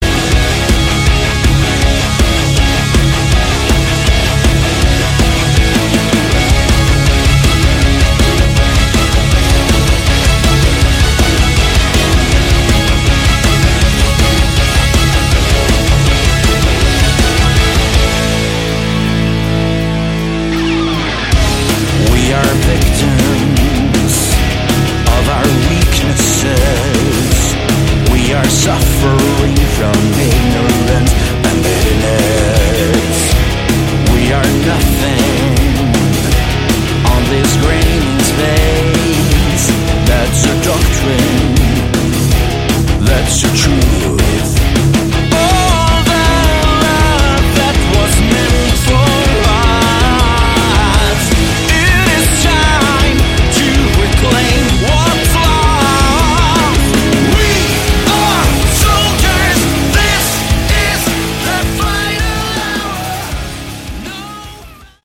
Category: Hard Rock/Melodic Metal
guitars
vocals
drums
bass
keyboards